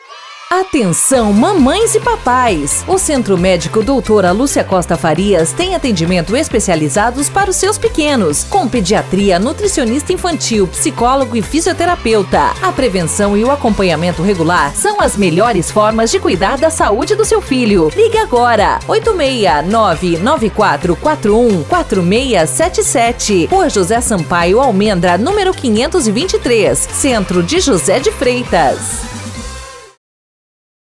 APAE de José de Freitas Realiza Arraiá dos Apaixonados
A festividade julina aconteceu no Centro Poliesportivo Rosa Carvalho, (Quadra da APAE).